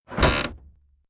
CHQ_FACT_crate_effort.ogg